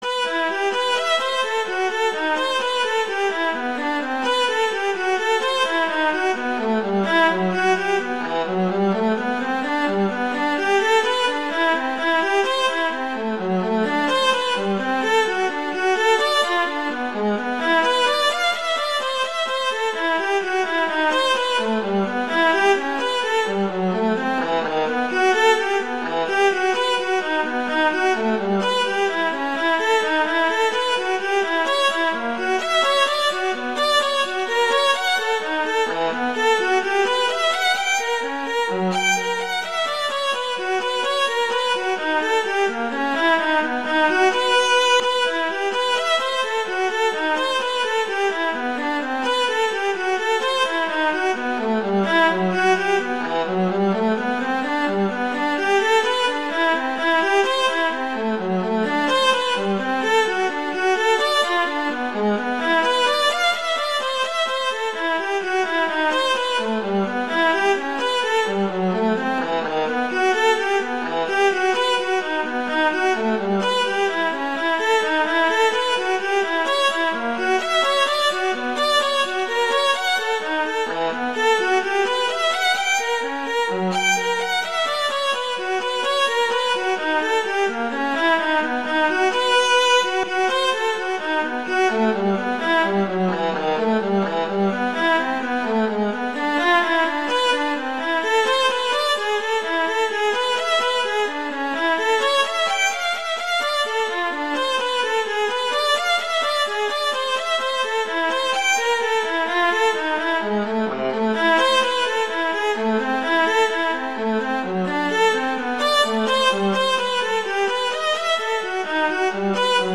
viola solo
classical